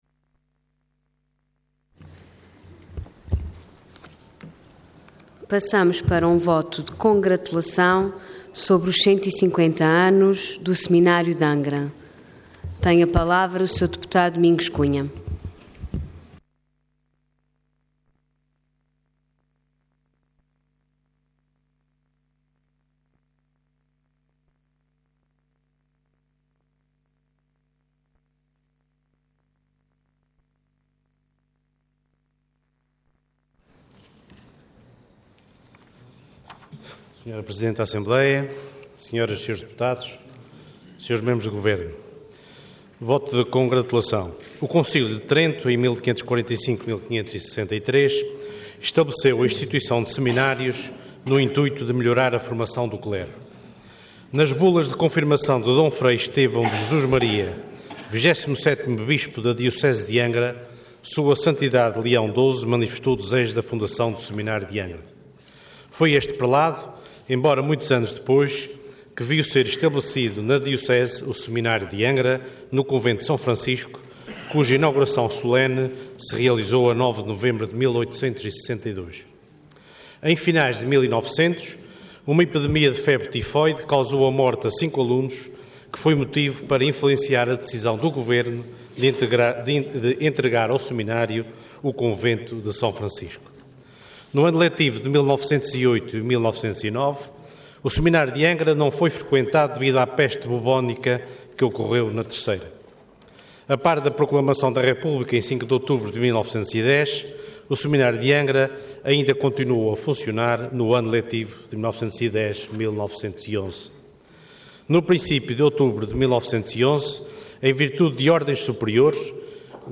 Intervenção Voto de Congratulação Orador Domingos Cunha Cargo Deputado Entidade PS